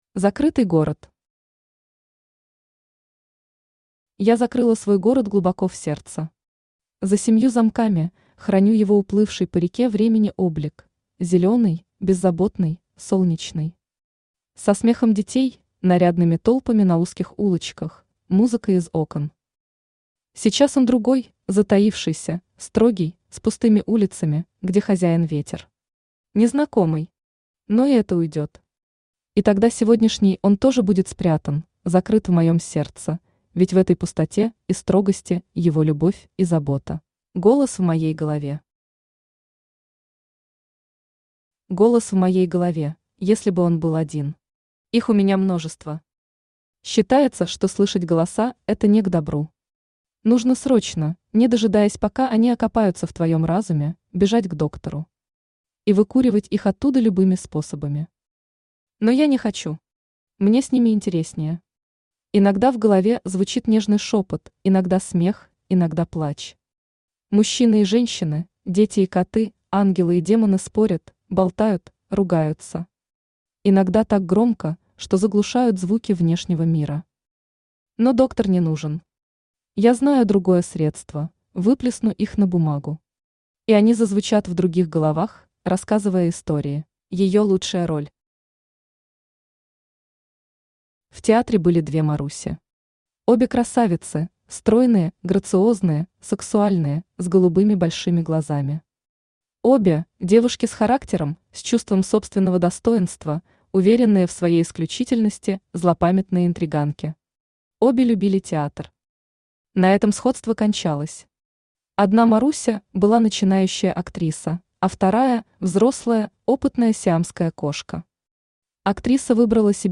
Аудиокнига Голос в моей голове | Библиотека аудиокниг
Aудиокнига Голос в моей голове Автор Елена Матеуш Читает аудиокнигу Авточтец ЛитРес.